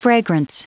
Prononciation du mot fragrance en anglais (fichier audio)
Prononciation du mot : fragrance